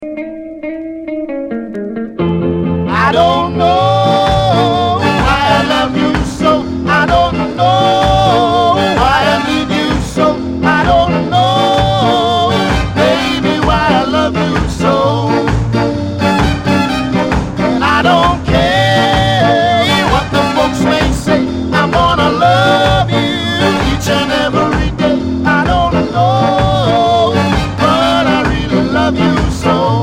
Reggae Ska Dancehall Roots Vinyl Schallplatten ...